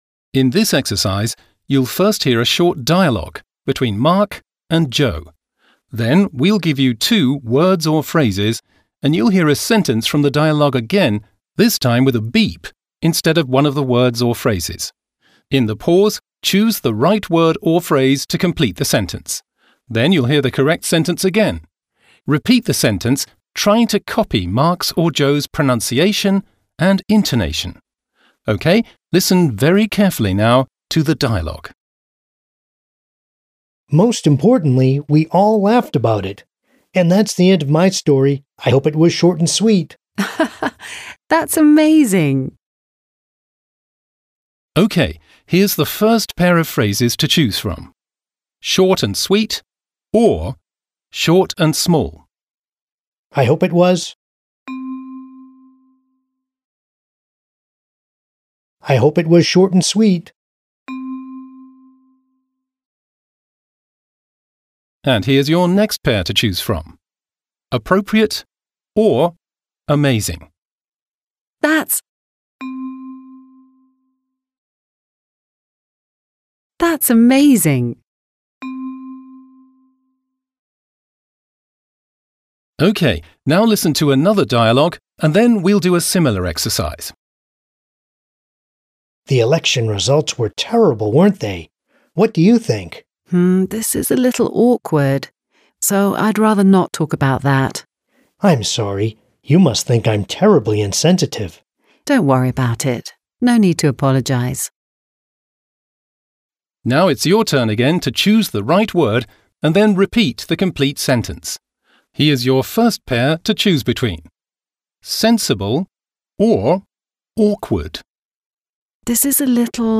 Business Skills - Small talk dialogues | ZSD Content Backend
Audio-Übung